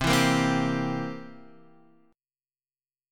C7 chord